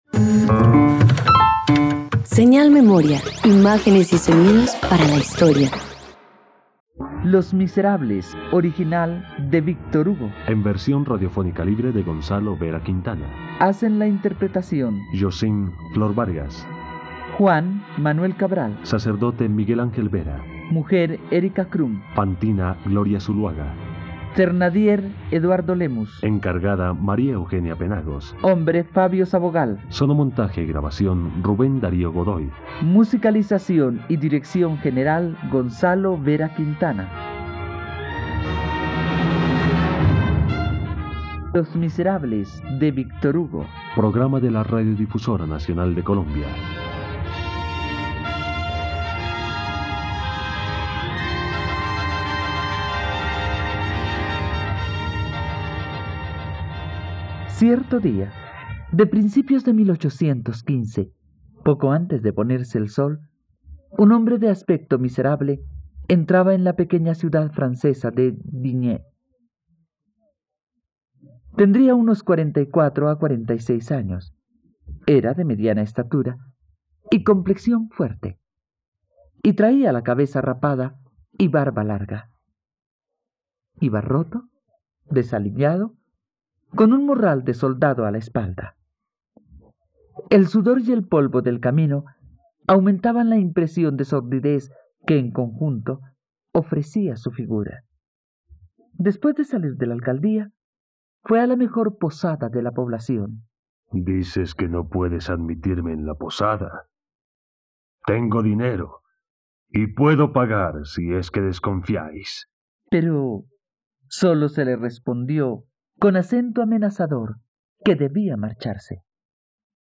..Radioteatro. Escucha la adaptación para radio de la obra "Los miserables" del novelista francés Víctor Hugo en la plataforma de streaming RTVCPlay.